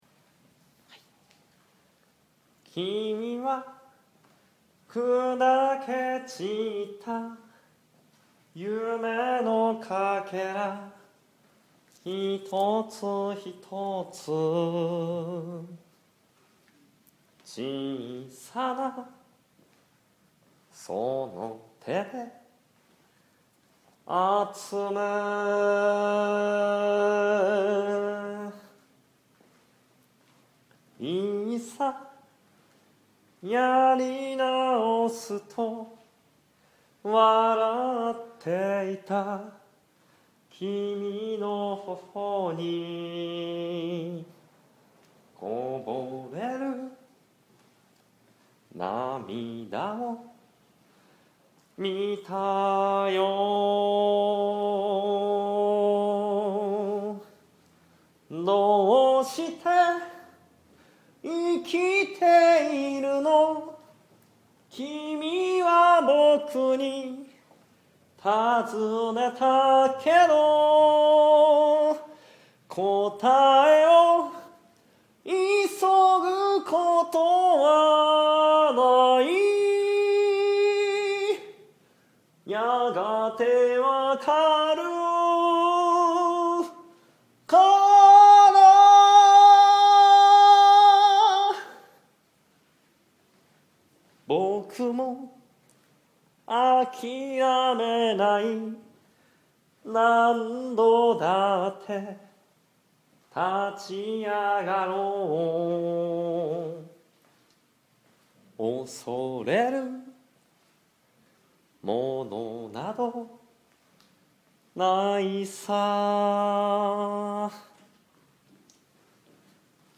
会場となったギャラリーLEDECOにて
本番の合間にフルコーラスで２曲、歌い上げていただきました。